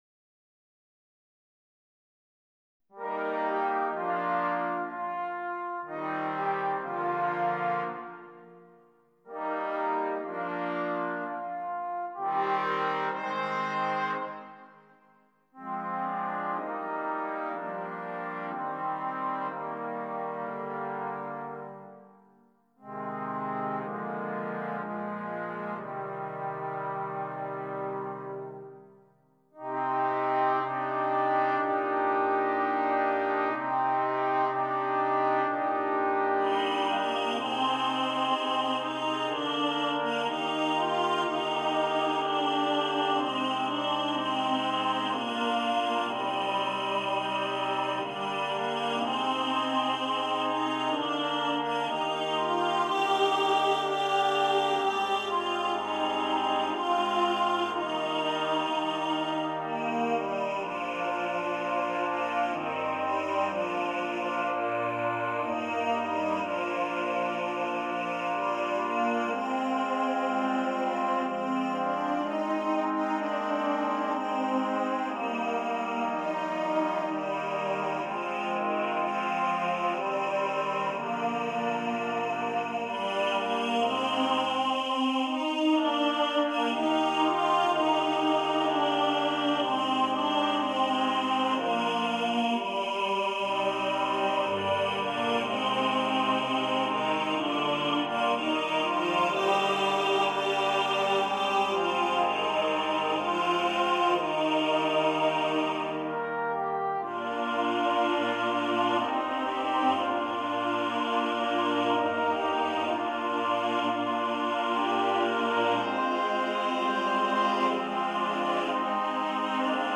TTBB + 4 Trombones
For Men’s Chorus and Four Trombones